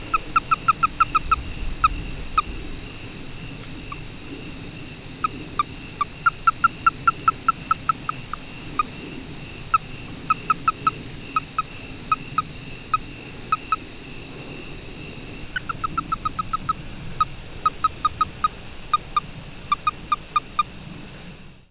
This is a short recording of the sounds of the release calls of an adult toad in Railroad Valley, Nye County, Nevada. The toad was picked up by hand and made these sounds. Insects and airplanes are heard in the background.
A release call is produced by a male toad or an unreceptive female toad when a frog or other animal (including a human hand) grabs it across the back in the position used for mating or amplexus.
railroadvalleytoadreleasecall817.mp3